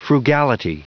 Prononciation du mot frugality en anglais (fichier audio)
Prononciation du mot : frugality